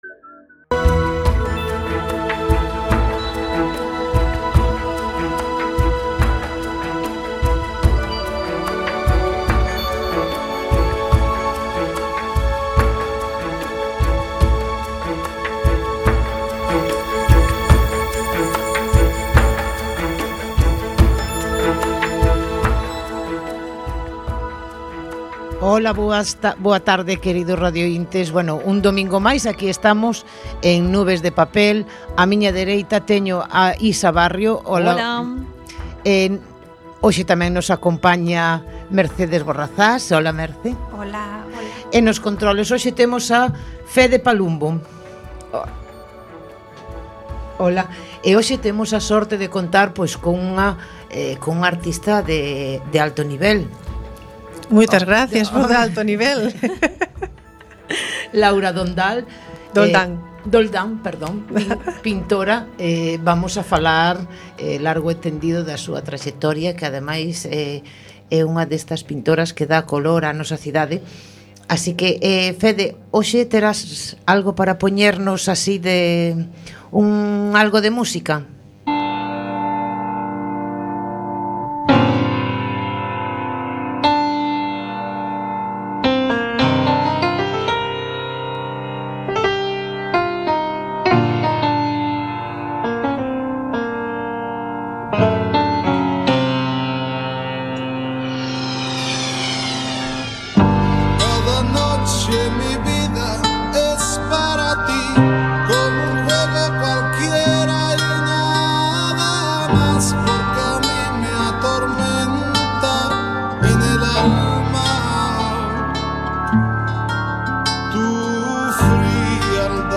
En nuestro programa Nubes de Papel abordaremos diversos temas literarios, entrevistas con autores y autoras, pasearemos por el mundo de la pintura, escultura y música de nuestra ciudad. La cultura de nuestra ciudad tendrá su hueco en el programa Nubes de Papel